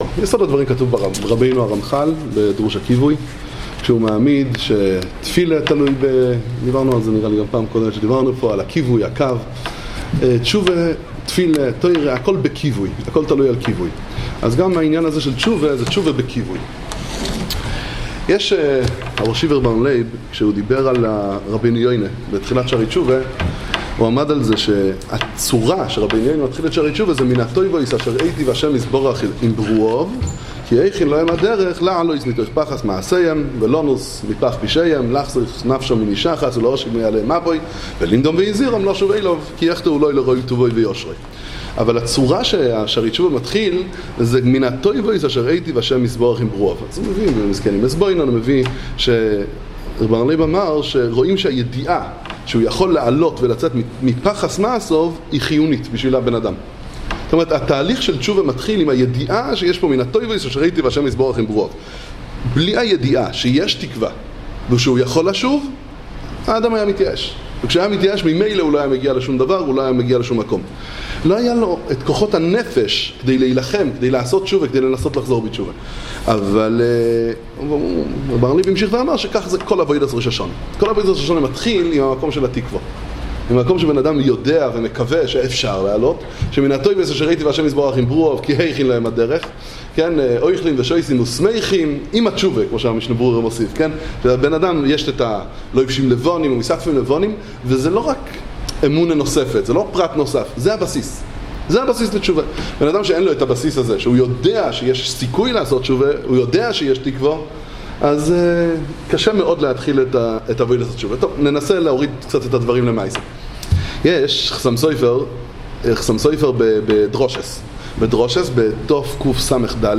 וועד הכנה לראש השנה